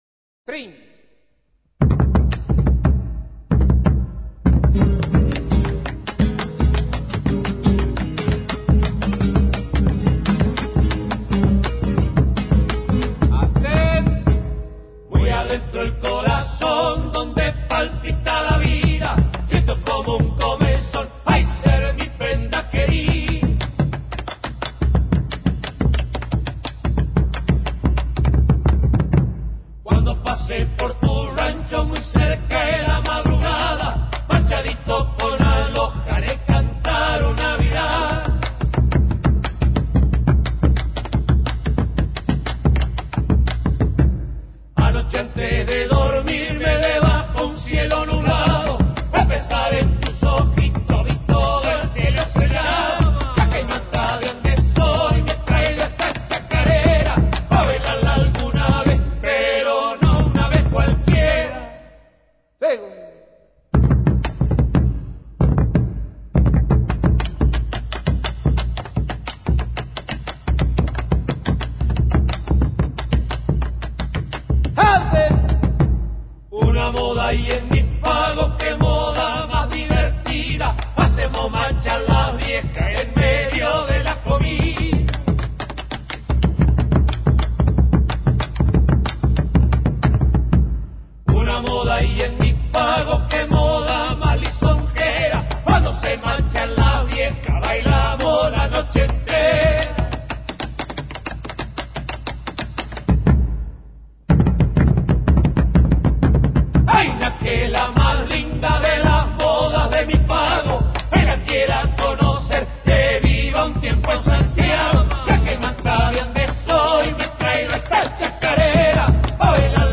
(chacarera)